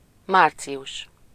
Ääntäminen
Ääntäminen France: IPA: [maʁs] Tuntematon aksentti: IPA: /maʁ/ Haettu sana löytyi näillä lähdekielillä: ranska Käännös Ääninäyte 1. március Suku: m .